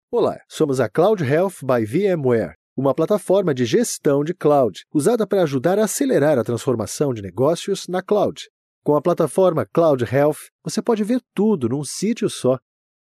Portugués (Brasil)
Natural, Amable, Seguro, Empresarial, Accesible
Audioguía